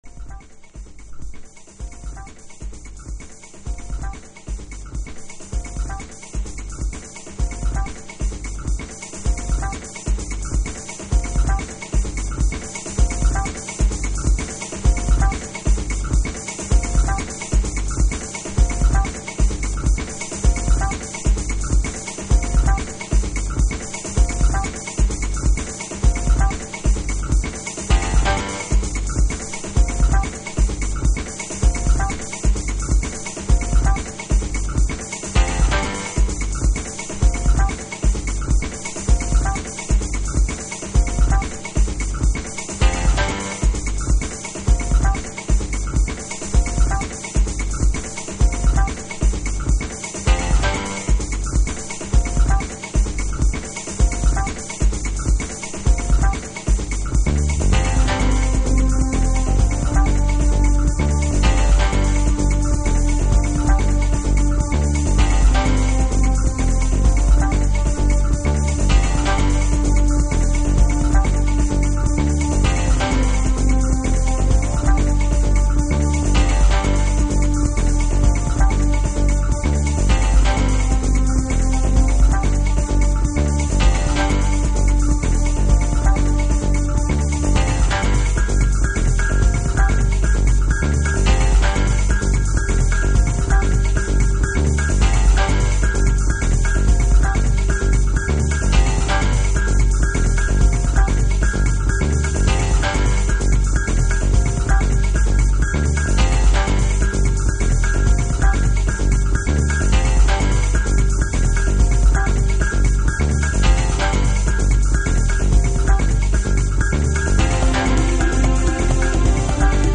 House / Techno
空間系シンセの大海原は相変わらず、今回ジャズ的なるトラックを制作しています。